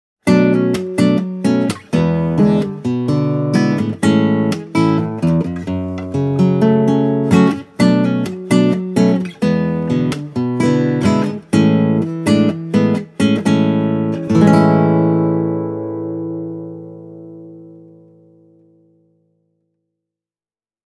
The Vivace C’s voice is very woody and a little bit dry. You can clearly hear the wood amplifying the string vibrations. The bottom end isn’t overpowering, the mid-range is warm, and the trebles sound open, but never too bright.
kantare-vivace-c.mp3